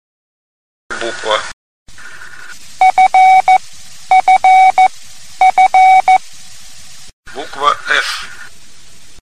Предлагаю для обучения приема использовать еще большее приближение к нашим занятиям - сперва звучит слово "Буква", затем три раза повторяется морзе и в это время называем букву (или цифру), затем слушаем правильный ответ.